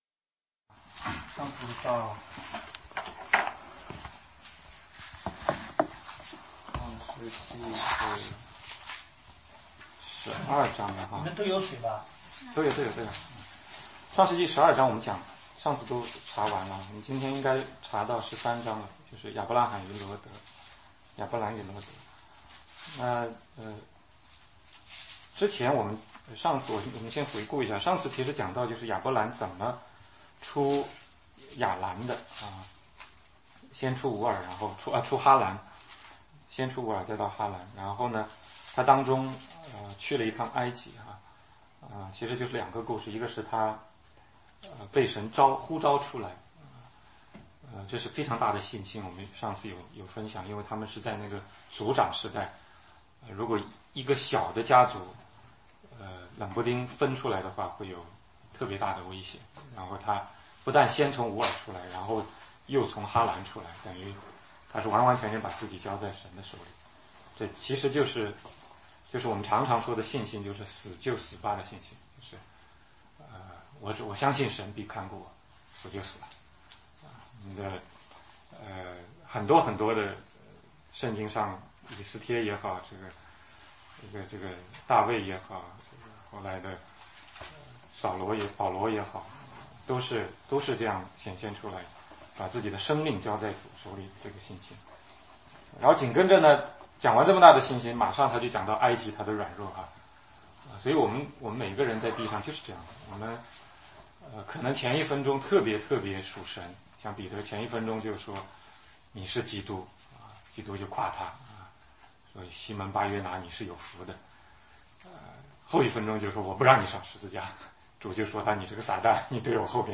16街讲道录音 - 创世纪13,14。 2017,01,16